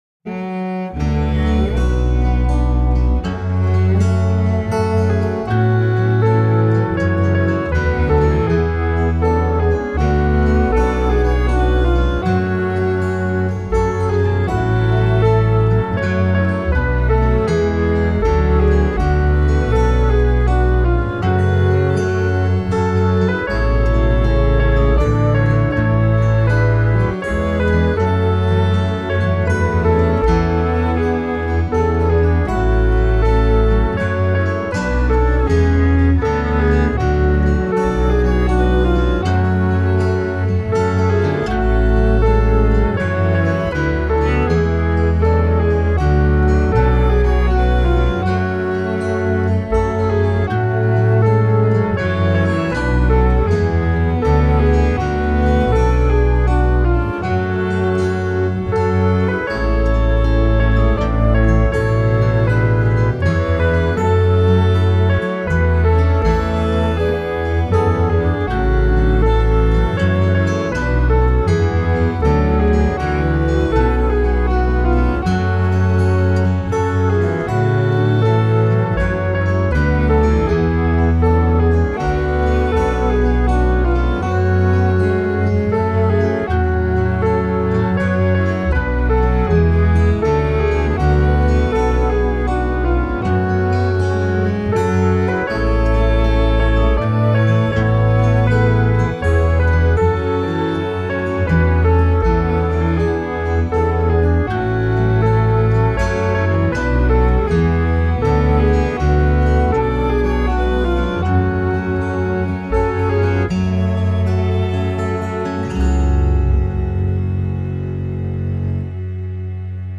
I’ve already blogged this song recently but I was playing with a MIDI file I found on the web and put it through lots of changes in BIAB and come up with all these odd dissonances.